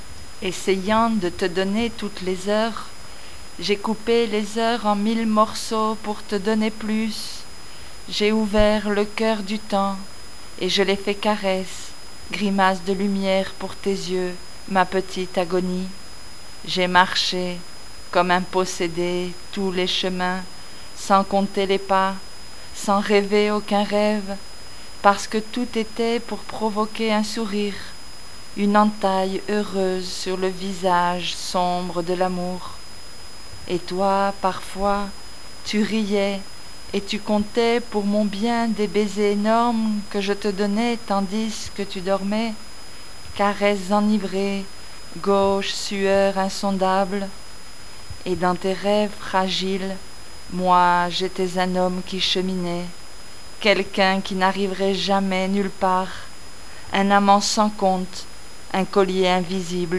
récite